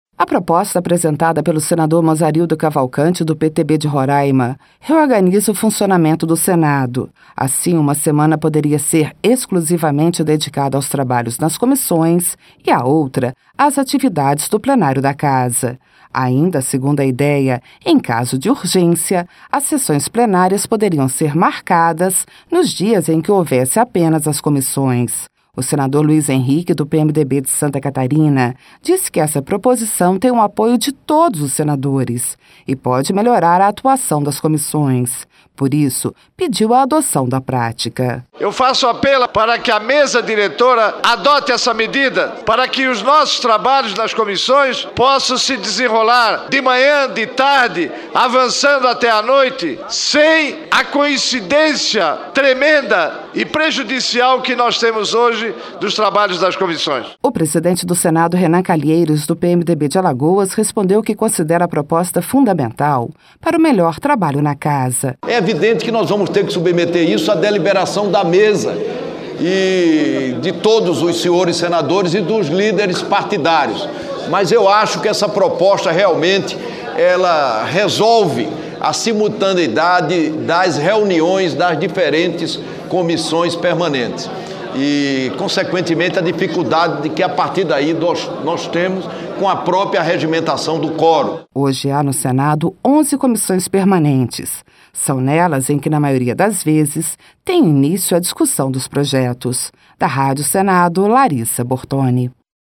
(Repórter) A proposta apresentada pelo senador Mozarildo Cavalcanti, do PTB de Roraima, reorganiza o funcionamento do Senado.